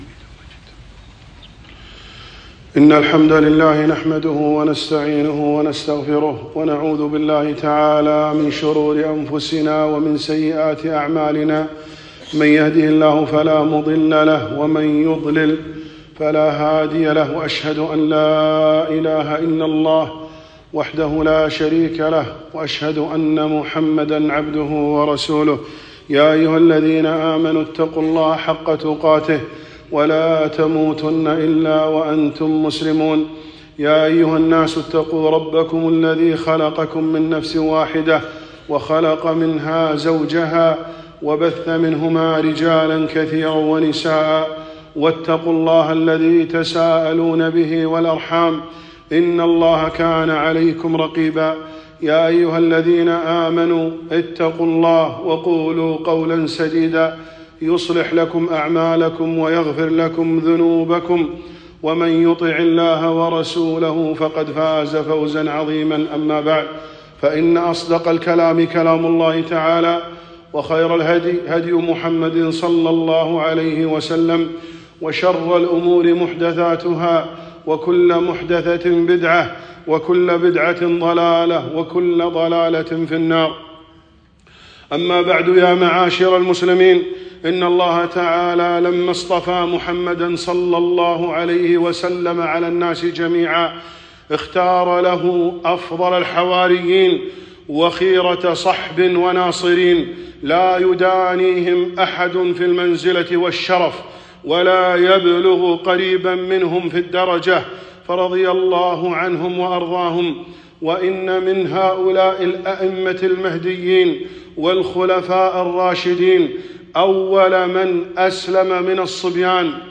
خطبة - علي بن أبي طالب رضي الله عنه